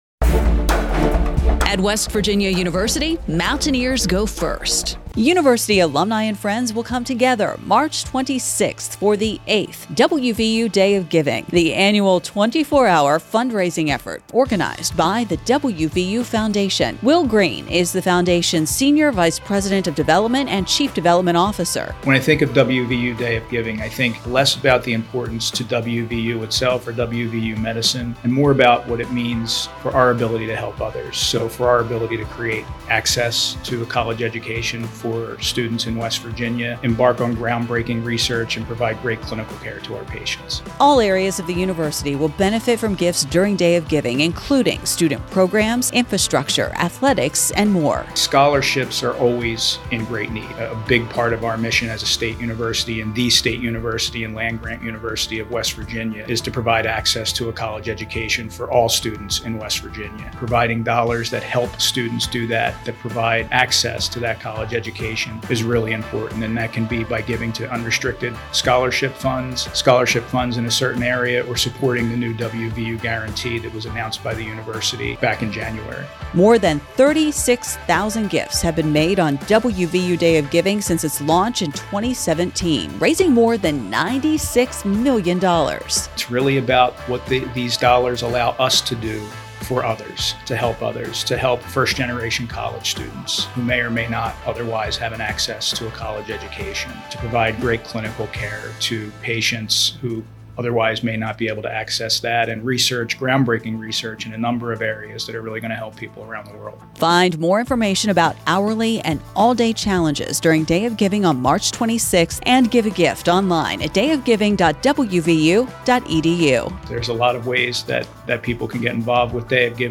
Day of Giving radio spot March 18
day-of-giving-radio-spot.mp3